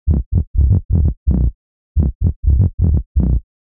• Short Techno Bass Presence.wav
Short_Techno_Bass_Presence___H66.wav